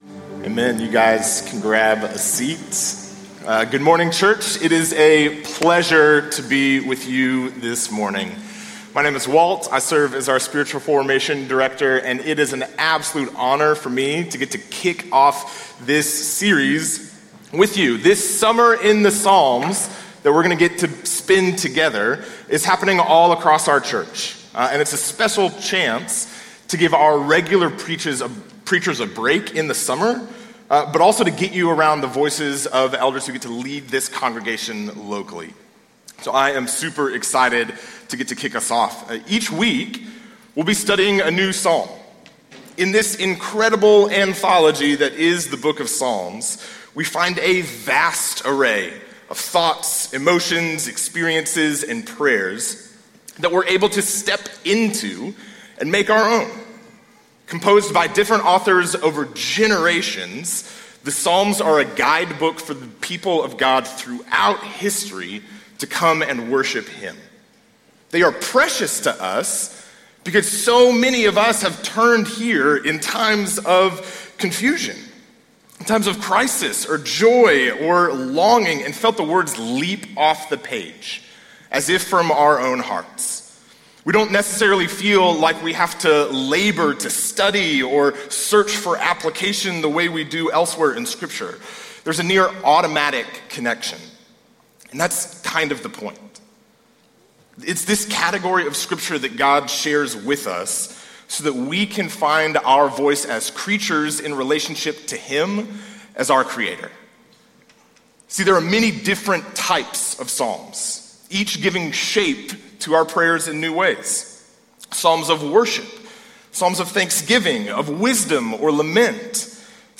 Download - Joy (Advent Spoken Word) | Podbean